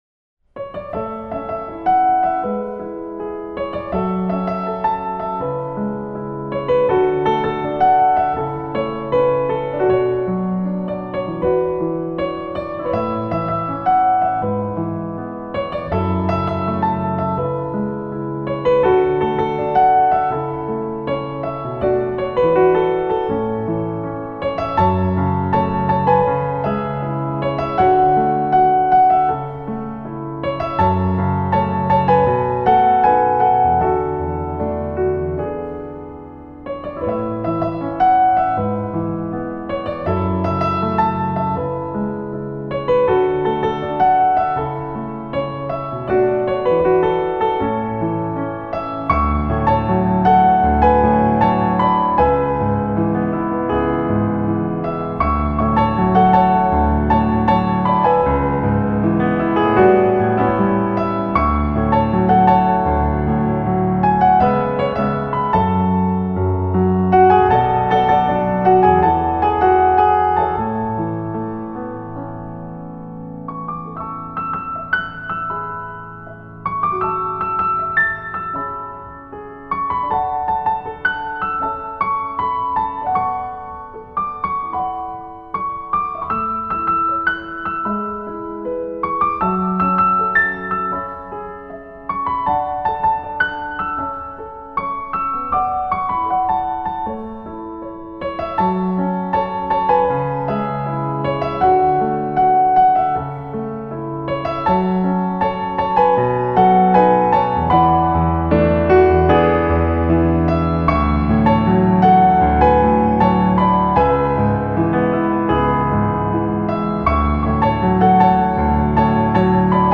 钢琴演奏